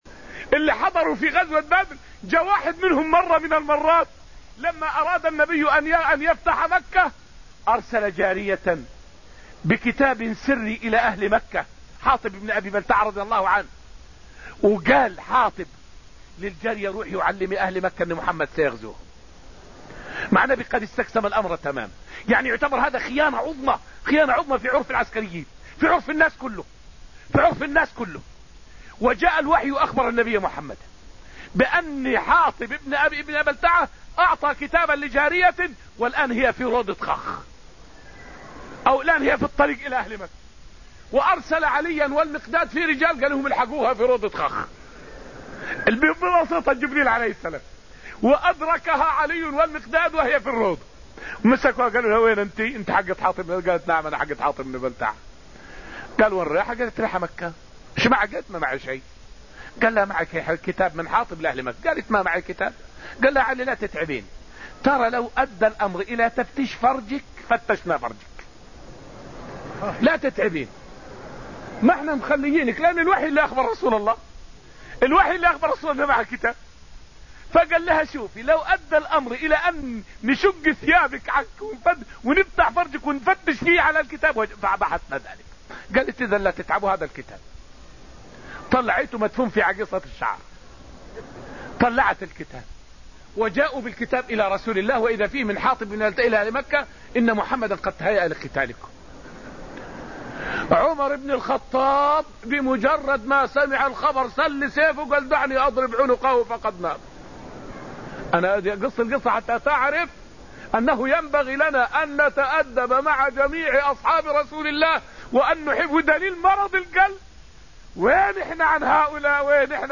فائدة من الدرس السابع عشر من دروس تفسير سورة الحديد والتي ألقيت في المسجد النبوي الشريف حول قصة كتاب حاطب إلى أهل مكة.